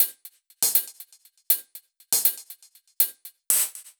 Index of /musicradar/french-house-chillout-samples/120bpm/Beats
FHC_BeatB_120-02_Hats.wav